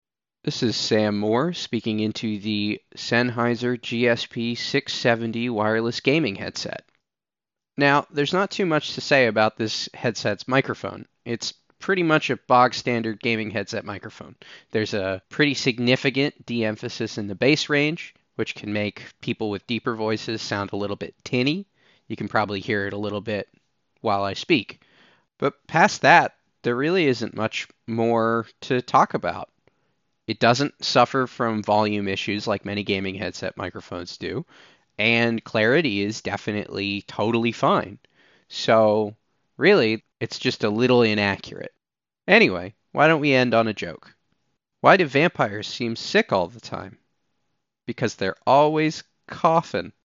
Sennheiser-GSP670-sample.mp3